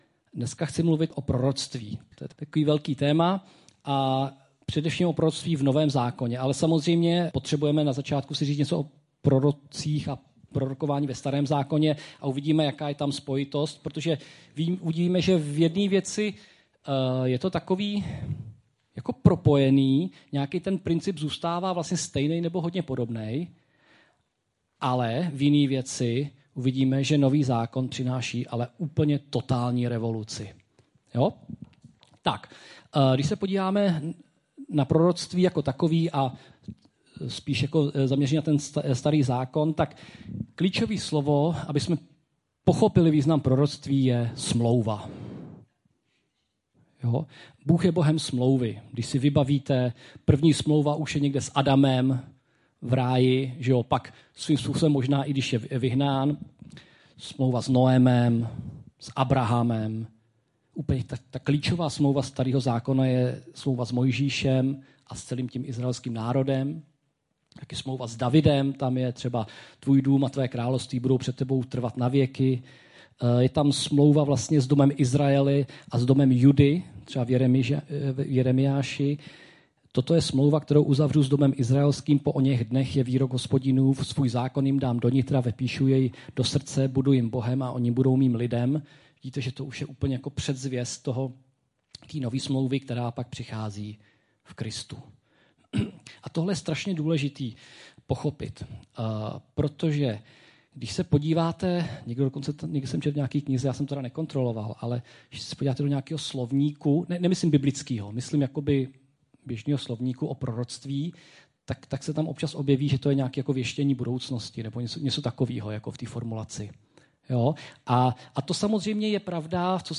vyucovani - Proroctvi.mp3